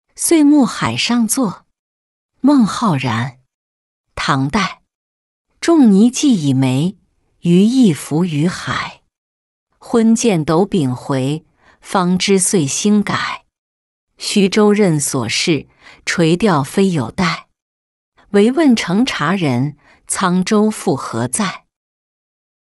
岁暮海上作-音频朗读